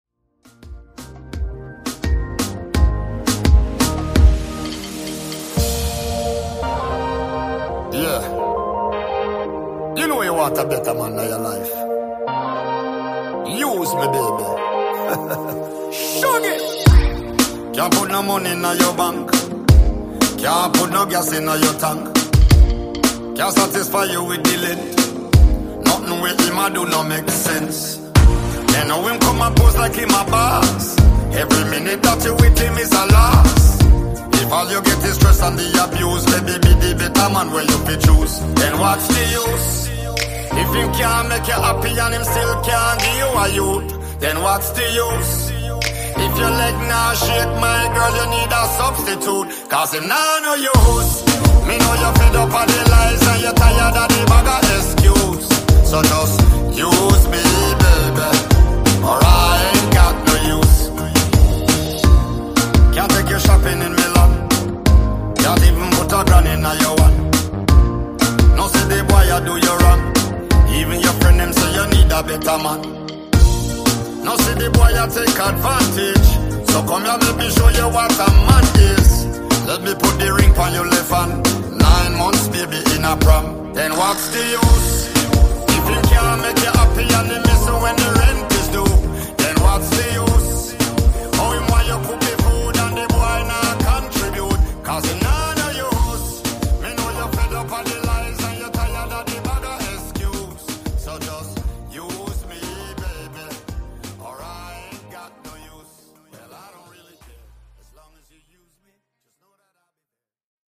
Genre: RE-DRUM
Clean BPM: 128 Time